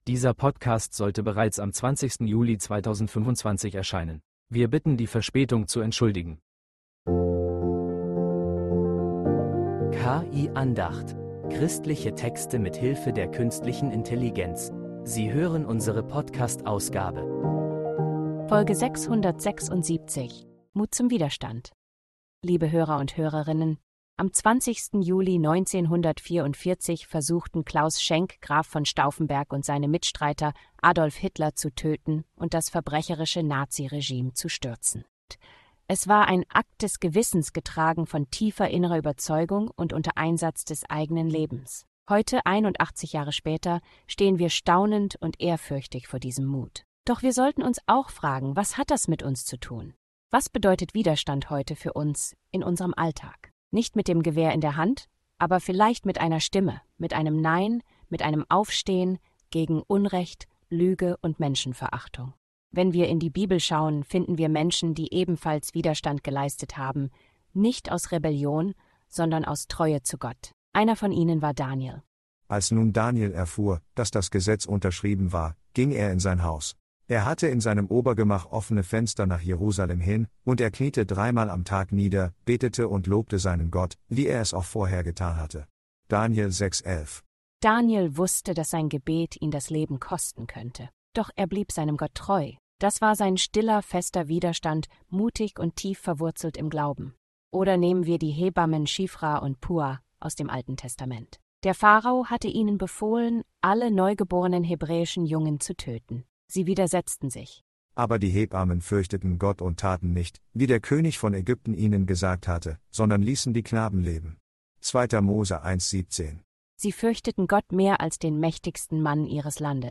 Diese Andacht stellt unbequeme Fragen und lädt ein, neue